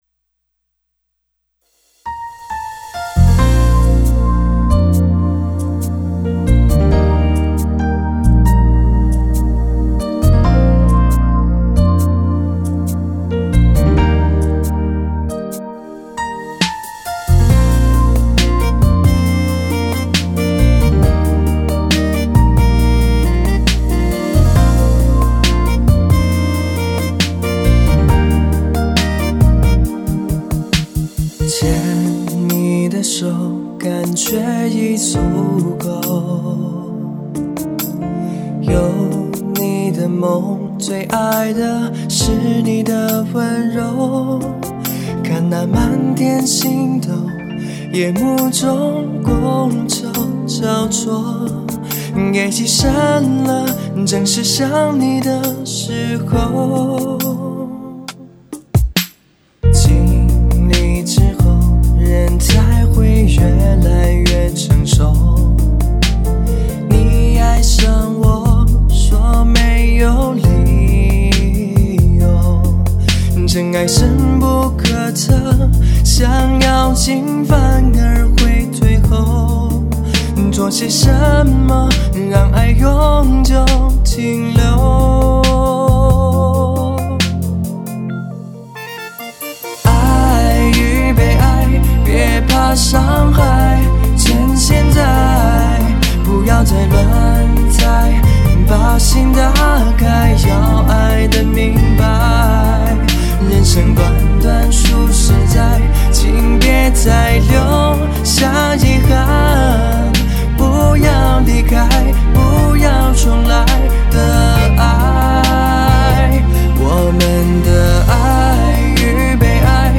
期待下这个好听的男声
旋律很好听。